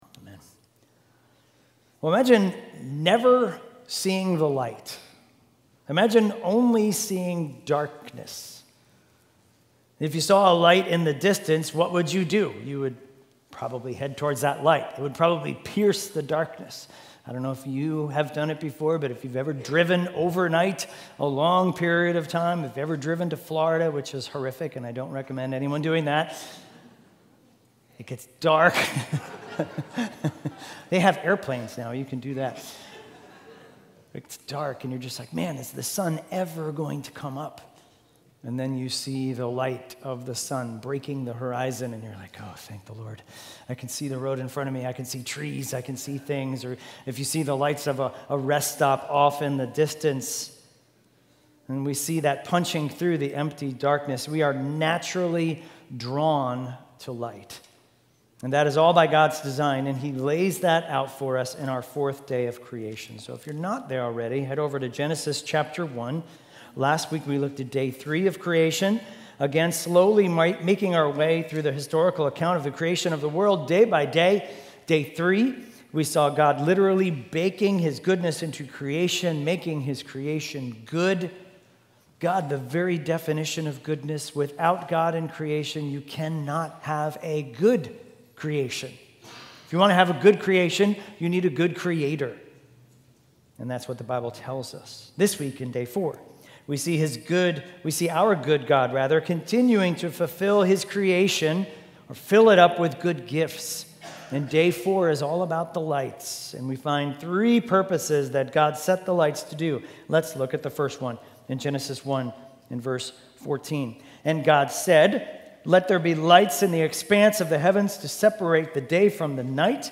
Weekly Sunday AM sermon series in Genesis.